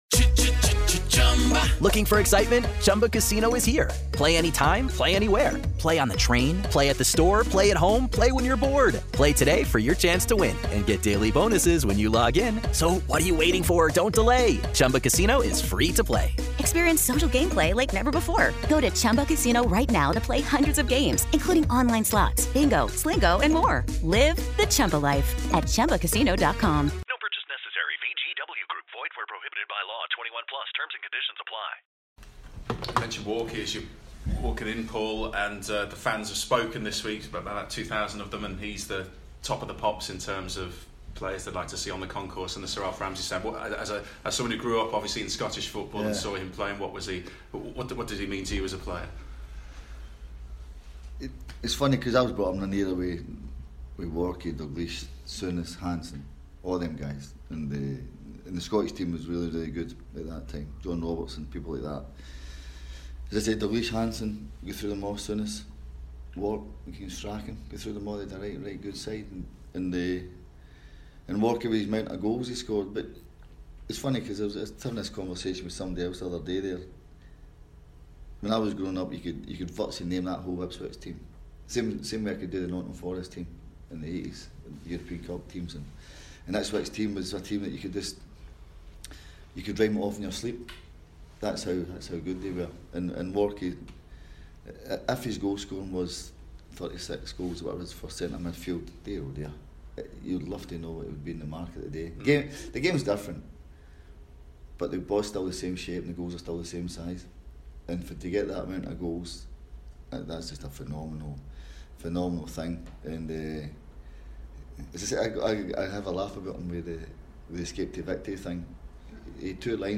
PRESS CONFERENCE: Lambert looks ahead to Town's clash with Blackpool
Paul Lambert spoke to the media this morning ahead of his side's visit to Blackpool